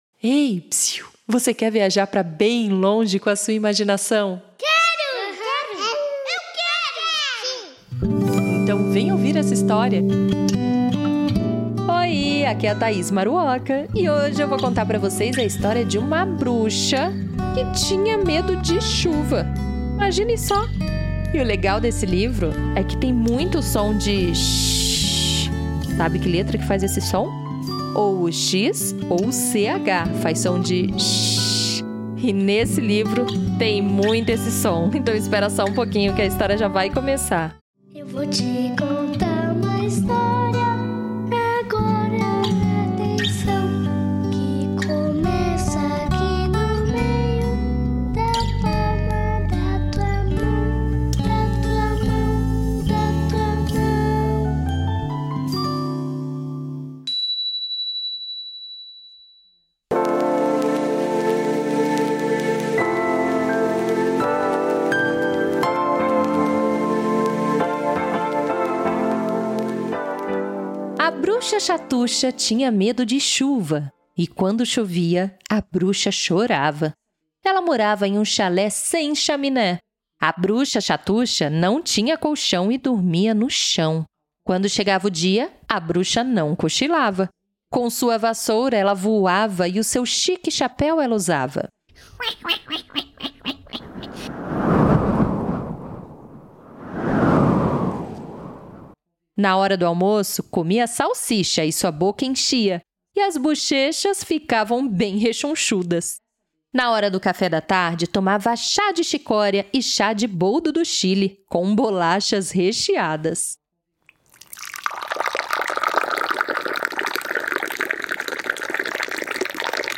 Uma história cheia de som, ritmo e humor.